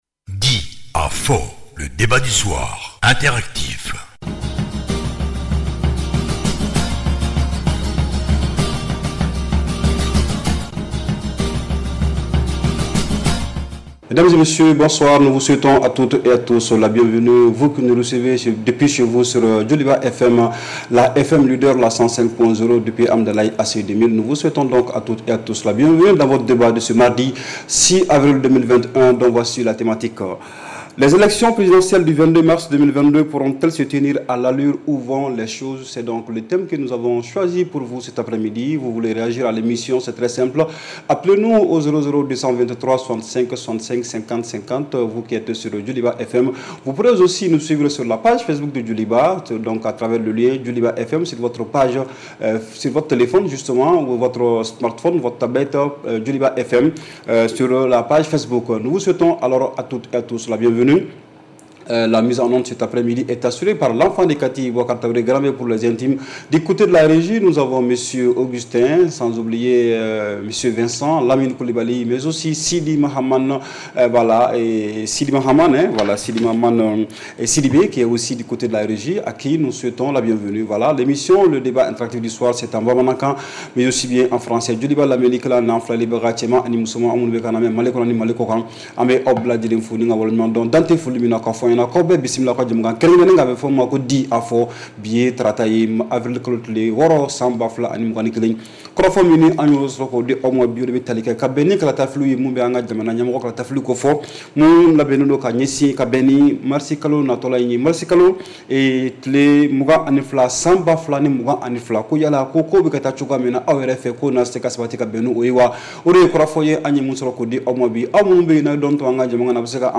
REPLAY 06/04 – « DIS ! » Le Débat Interactif du Soir